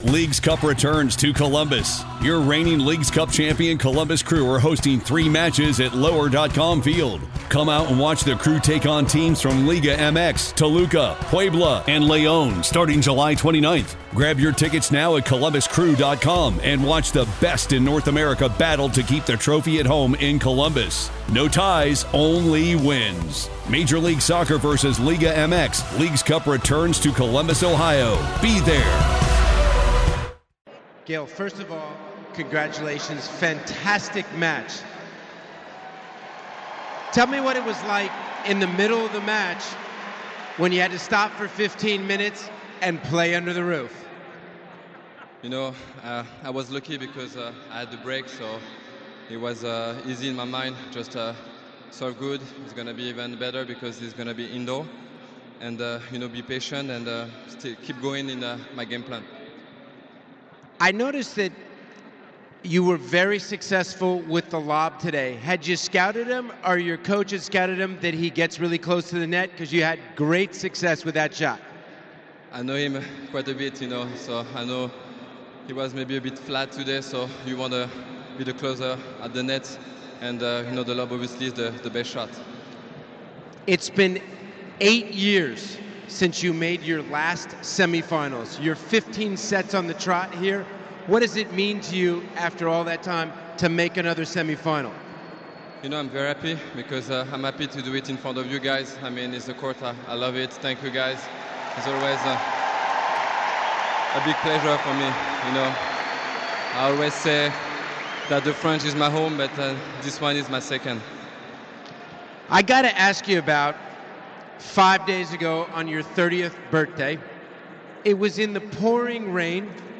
Gael Monfils speaks about his victory over.